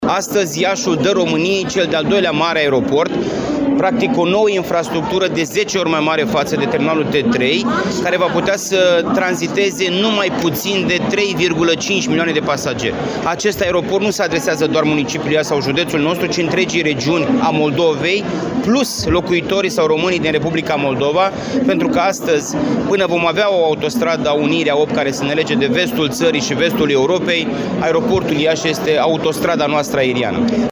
La Iași, a avut loc astăzi recepția lucrărilor la terminalul 4 al Aeroportului Internațional, investiţie care a fost finalizată la sfârşitul lunii decembrie 2023.
Președintele CJ Iași, Costel Alexe a declarat că odată cu finalizarea acestui proiect, Aeroportul Internațional Iași este legătura cu vestul țării, în lipsa unei unei autostrăzi care să lege cele două regiuni ale țării: ”Astăzi, Iașul dă României, cel de-al 2-lea mare aeroport, practic o nouă infrastructură de 10 ori mai mare față de terminalul T3, care va putea să tranziteze nu mai puțin de 3,5 milioane de pasageri.